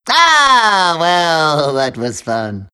Worms speechbanks
orders.wav